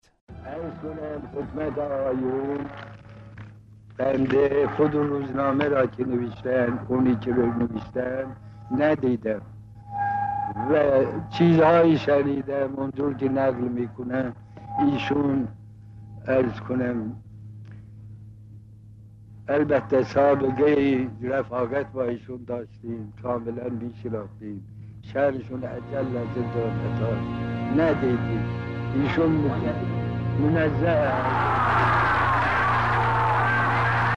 سپس جمعیت به‌جلوی منزل مرحوم علامه طباطبایی(ره) آمد.
صوت بیان علامه در قیام ۱۹ دی ماه ۱۳۵۶
سخنرانی-علامه-19-دی.mp3